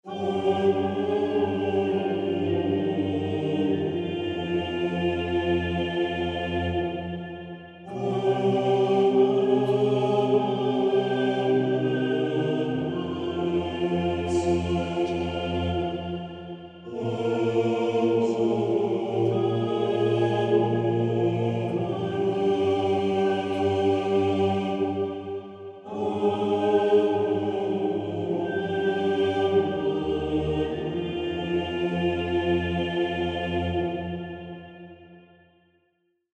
classique - orchestre - opera - vieux - chef